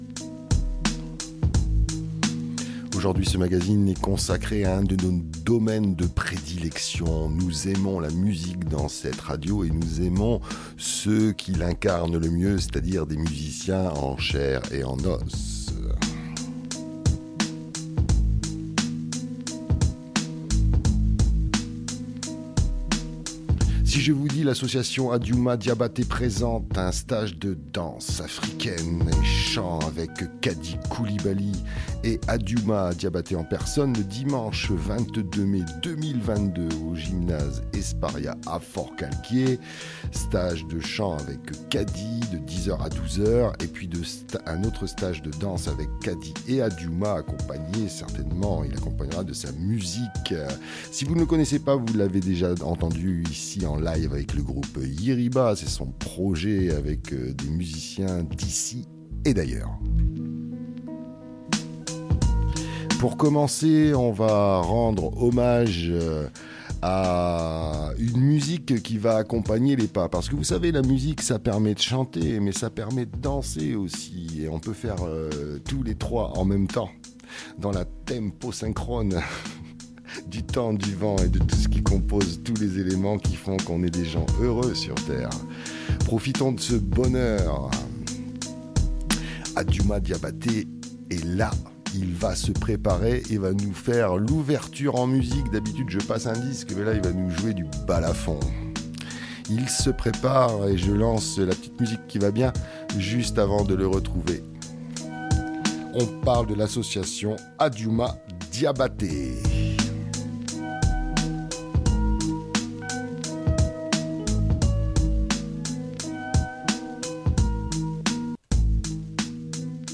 Nous l'entendrons dans cette entrevue nous parler de son association et des actions menées par ses adhérents.